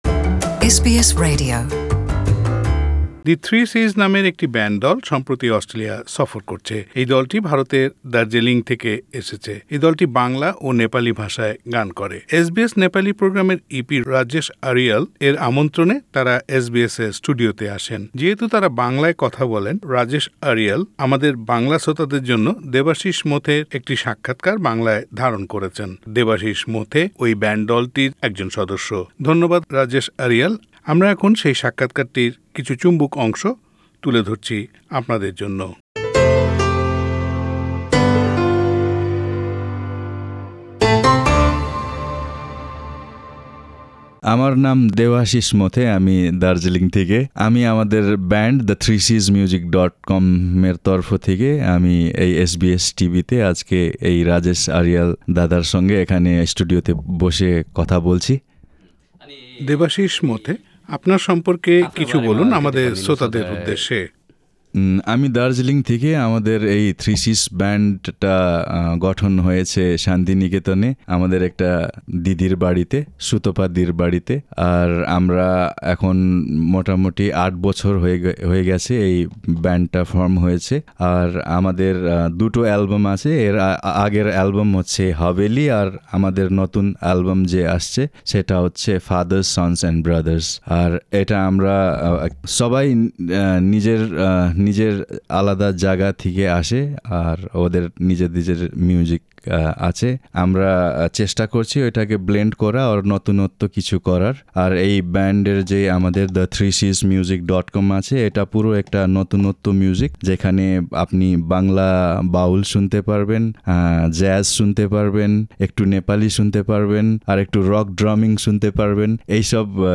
Inter-cultural music ensemble Interview